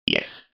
جلوه های صوتی
دانلود صدای ربات 37 از ساعد نیوز با لینک مستقیم و کیفیت بالا